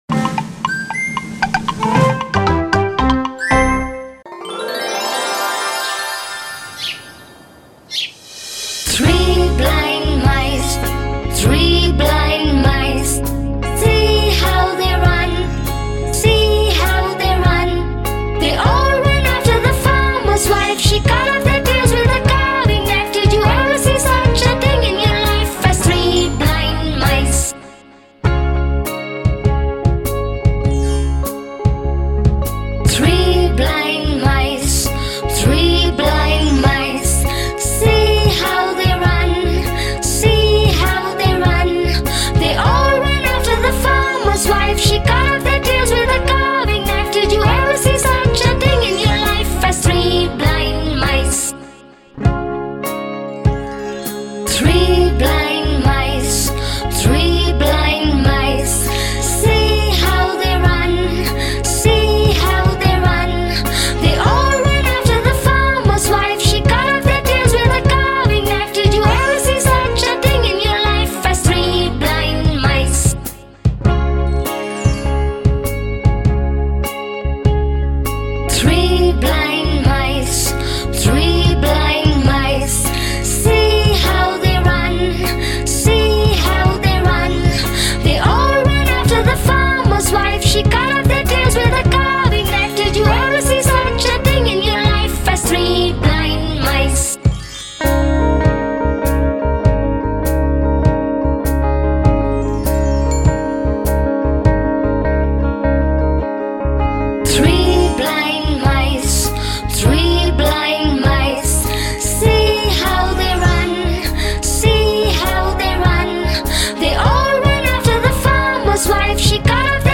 Three Blind Mice - Famous Nursery Rhyme Collection  Children Songs.mp3